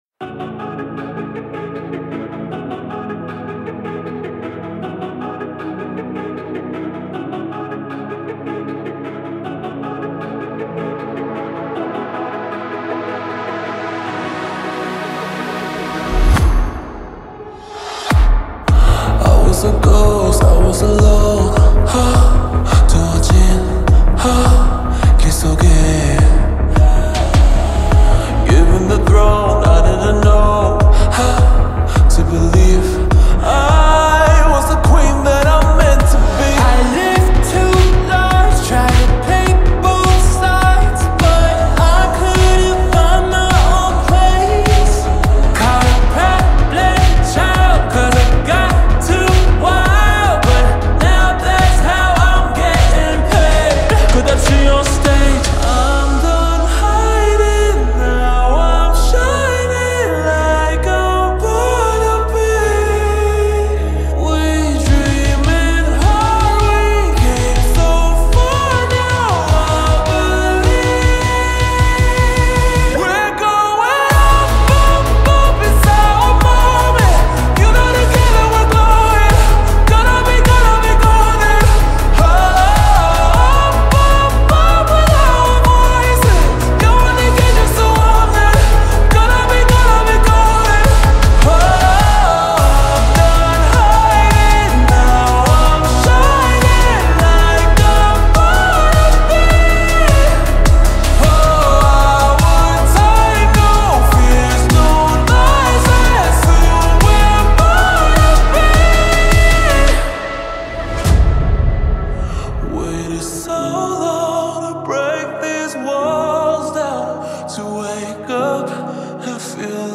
نسخه کند شده و Slowed